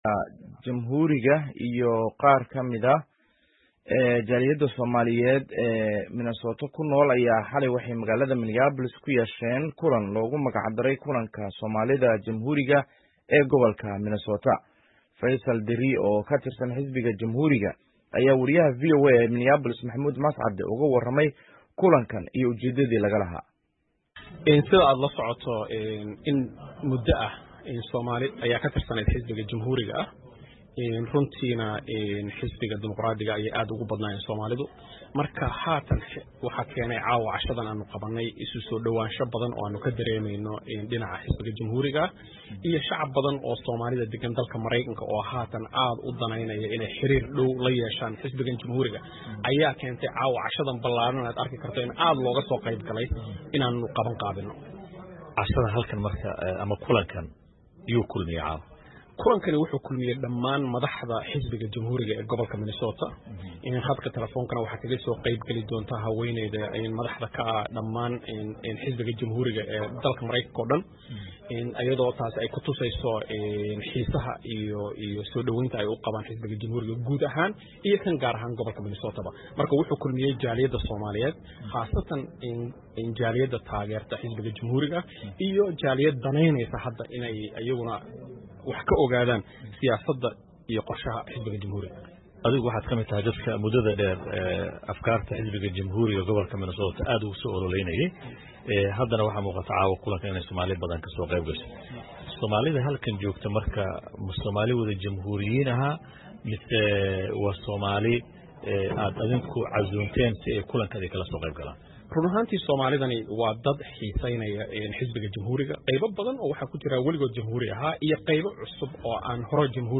Wareysi: Xisbiga Jamhuuriga iyo Soomaalida Minnesota oo kulmay